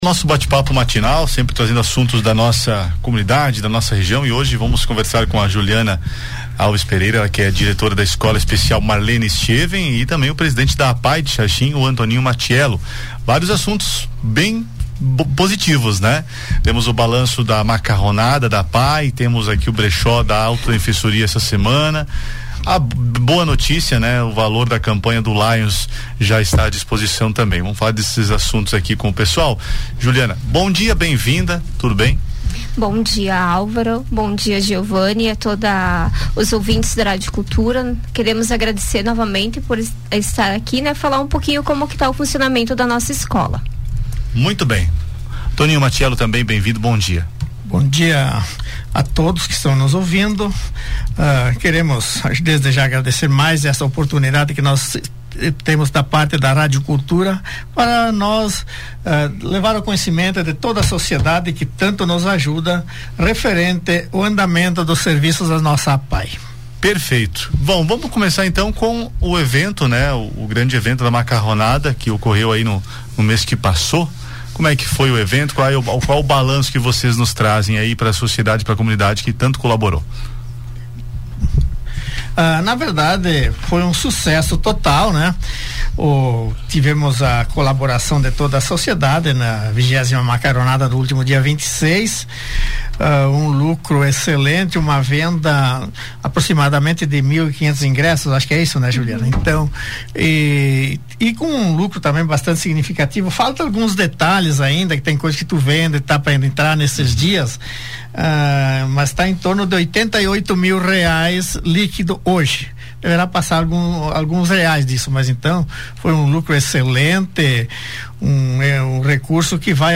No bate-papo matina no Conexão Entrevista